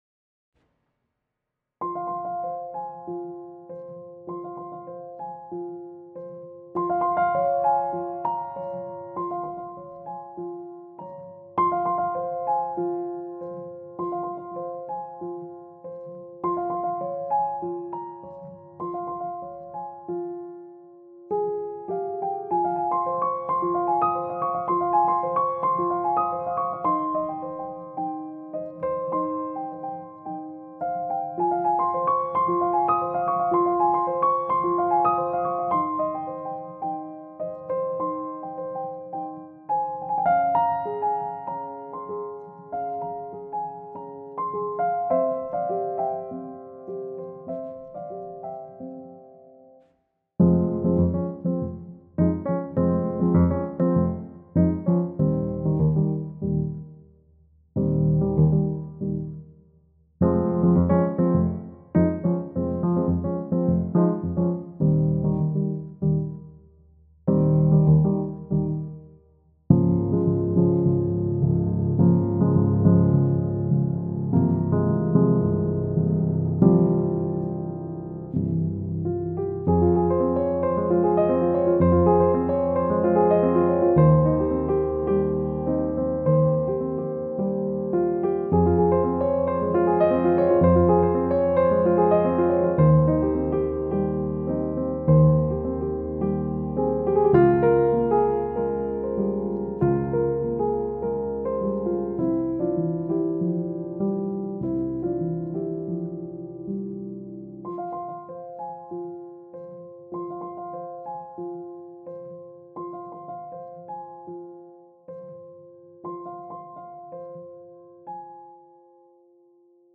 est une pièce tendre, une berceuse.
• Tonalité : Fa mineur
• Mesure(s) : 4/4 (petit passage en 2/4)
• Indication(s) de Tempo : Moderato (env. ♩= 108)